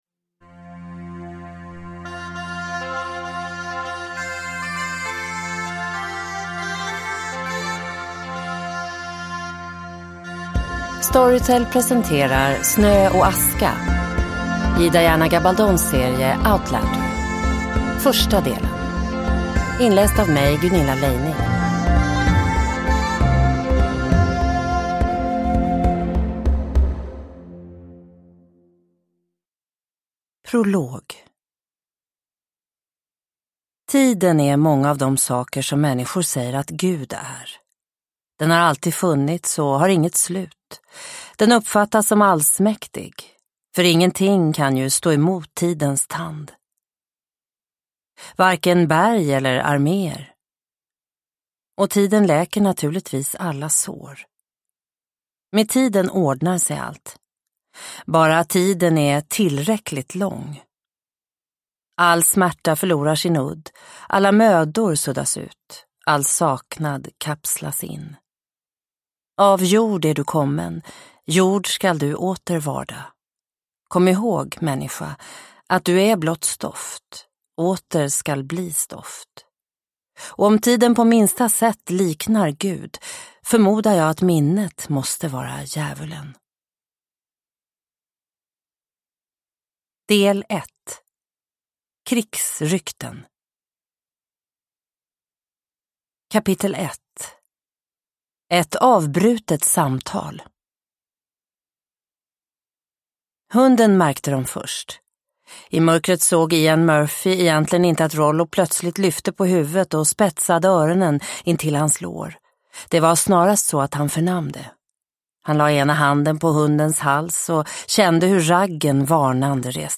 Snö och aska - del 1 – Ljudbok – Laddas ner